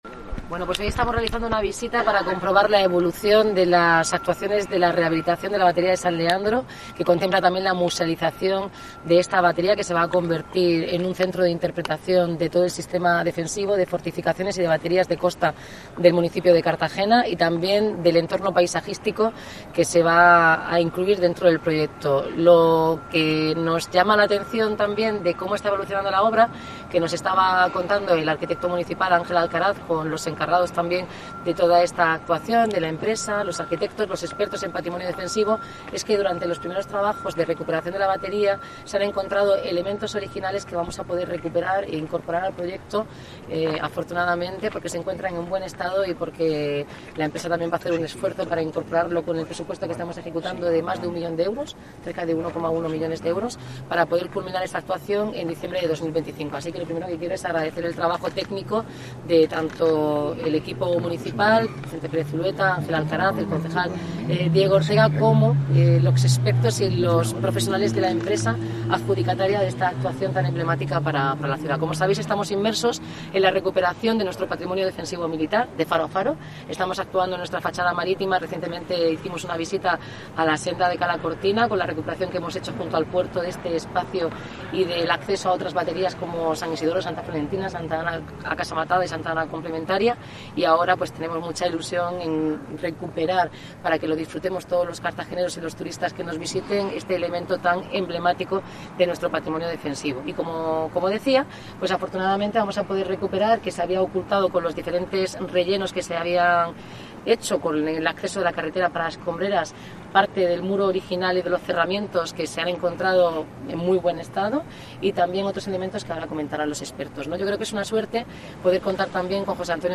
Audio: Declaraciones de la alcaldesa Noelia Arroyo durante la visita a la batería de San Leandro, en Cartagena.